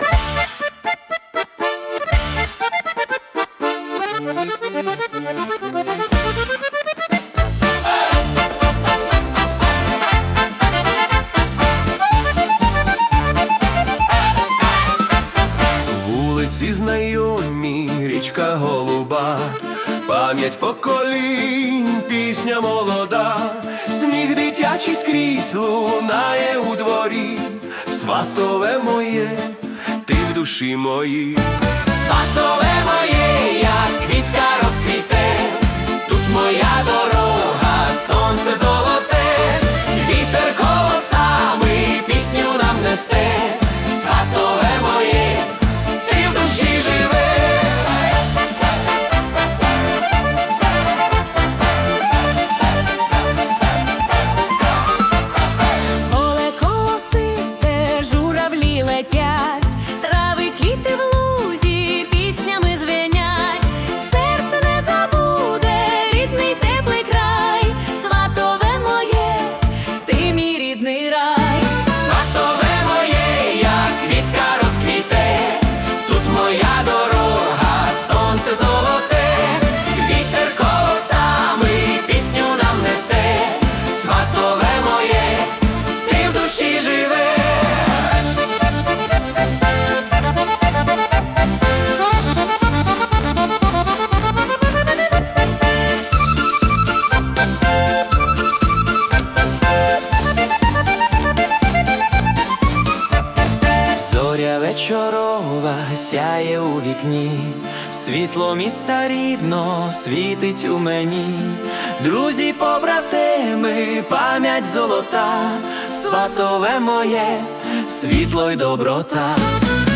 🎵 Жанр: Ukrainian Polka
тепла, сонячна полька
120 BPM